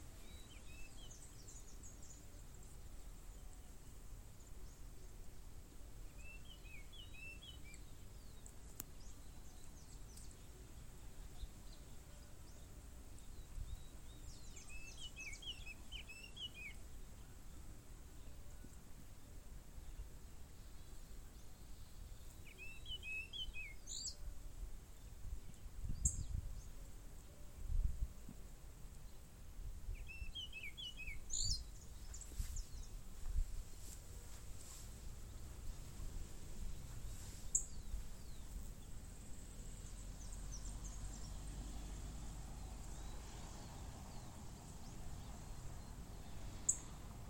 Chiguanco Thrush (Turdus chiguanco)
Life Stage: Adult
Location or protected area: Santa María
Condition: Wild
Certainty: Observed, Recorded vocal